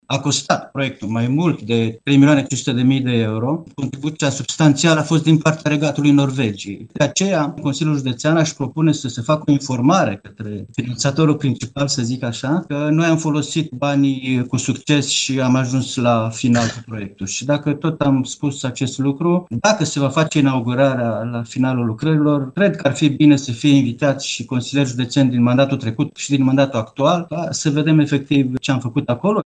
Regatul Norvegiei, unul din importanții finanțatori ai reabilitării conacului Mocioni, ar trebui informați despre stadiul lucrării, a atras atenția unul dintre consilierii județeni în ședința de plen de astăzi.
Consilierul județean Petre Mihăieș a intervenit la discuția CJT pe marginea finalizării lucrărilor de la Foeni, propunând o inaugurare a obiectivului de patrimoniu.